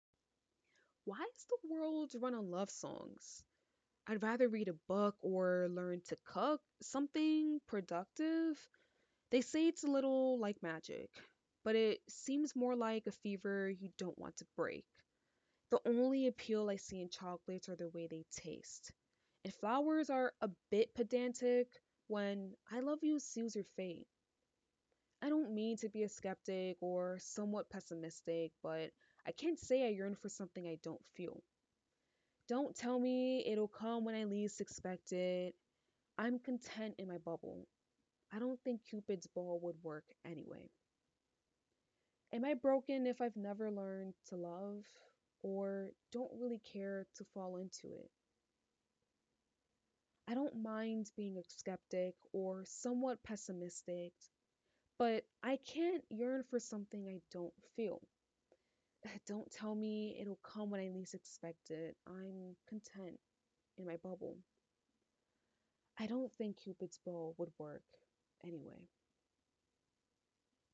spoke word (demo)